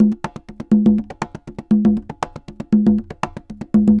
Congas_Salsa 120_3.wav